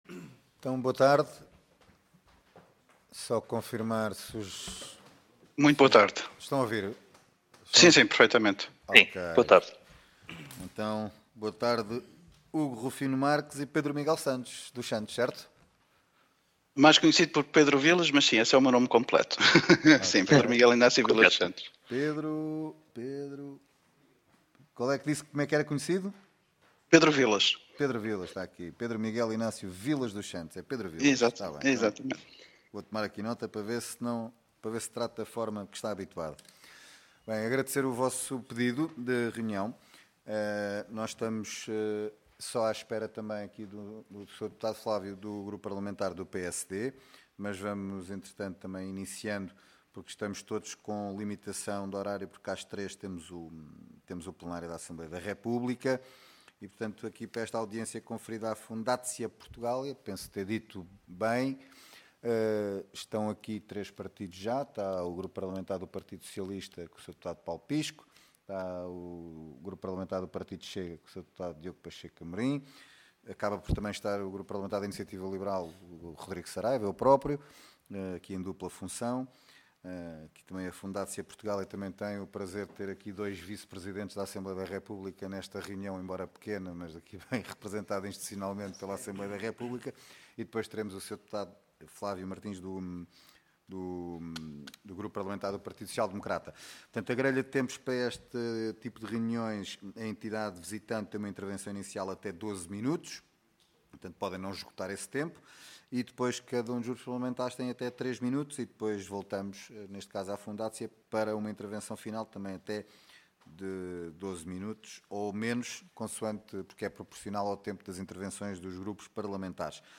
Comissão de Negócios Estrangeiros e Comunidades Portuguesas Audiência Parlamentar Nº 24-CNECP-XVI Assunto Apresentação da Fundacja Portugalia, seus membros e objetivos. Abordagem dos desafios existentes da diáspora portuguesa na Polónia